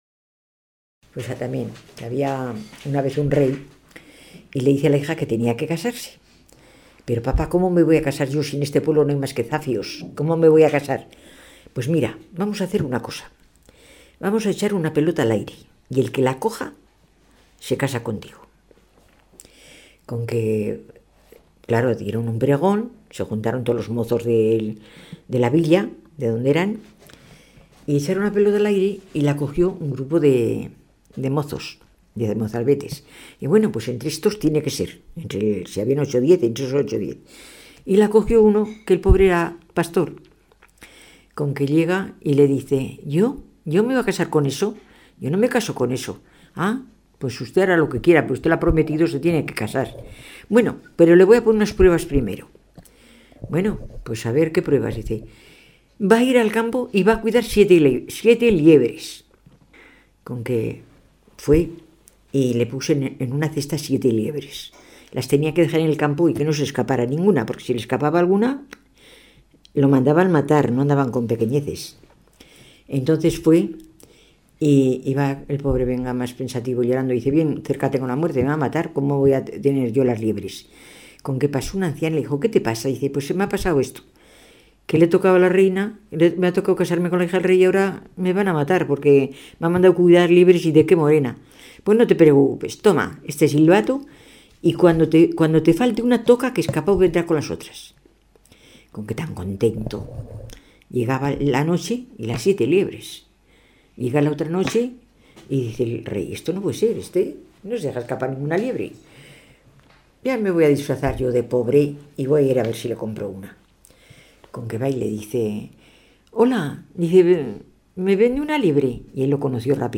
Clasificación: Cuentos
Lugar y fecha de recogida: Logroño, 6 de agosto de 2001